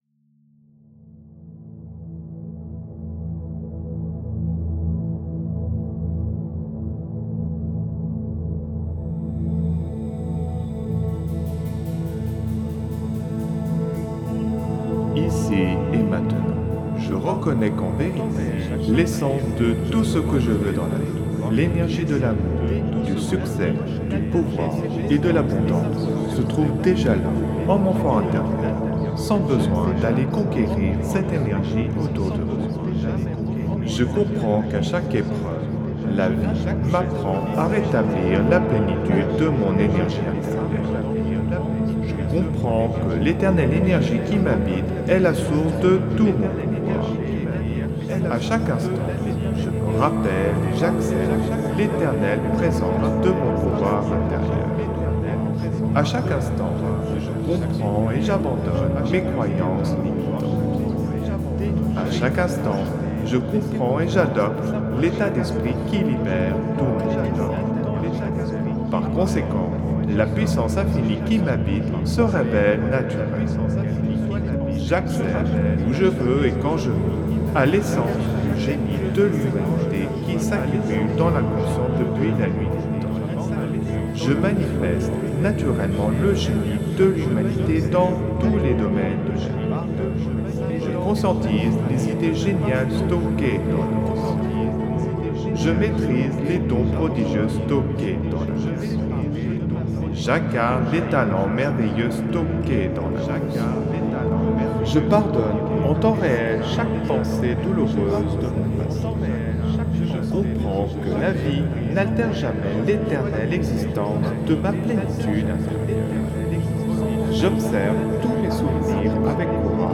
(Version ÉCHO-GUIDÉE)
Alliage ingénieux de sons et fréquences curatives, très bénéfiques pour le cerveau.
Pures ondes gamma intenses 65,19 Hz de qualité supérieure. Puissant effet 3D subliminal écho-guidé.